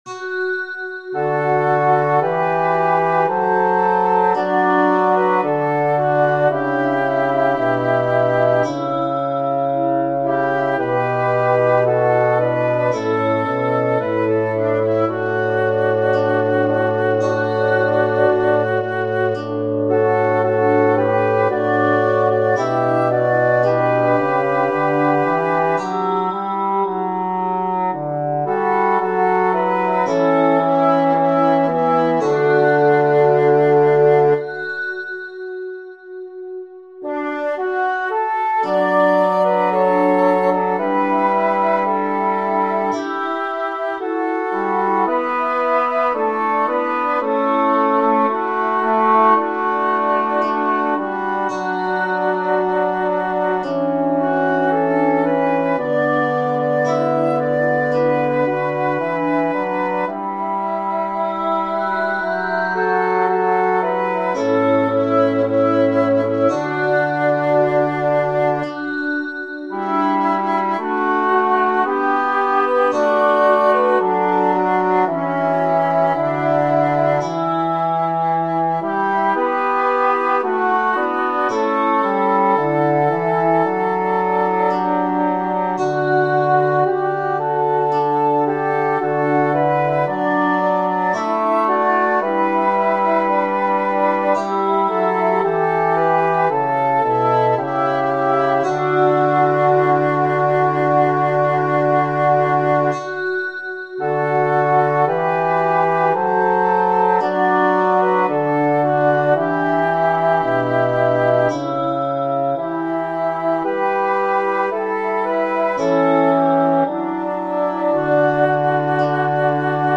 "Склянки" -- здесь это такие колокола из трубок.